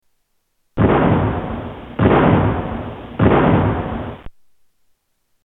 3 shotgun blasts
Category: Sound FX   Right: Personal